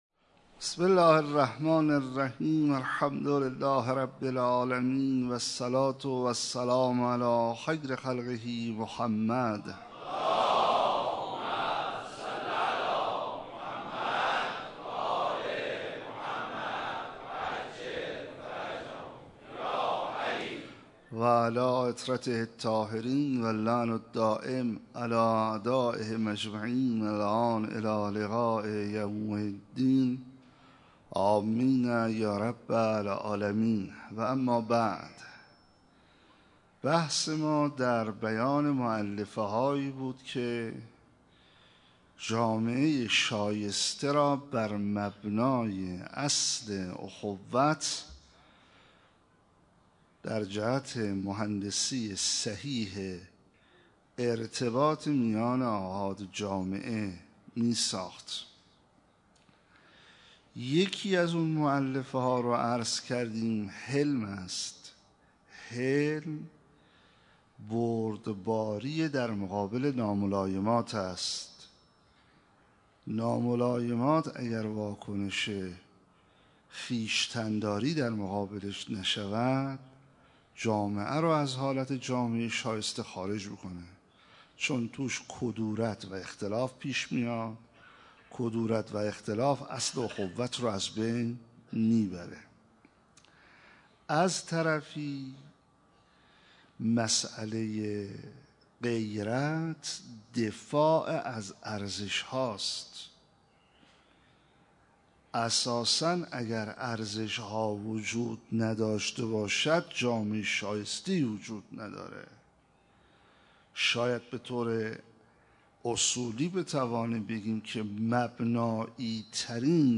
شب هفتم محرم 97 - مسجد حضرت امیر - مردم در جامعه شایسته جلسه 7
سخنرانی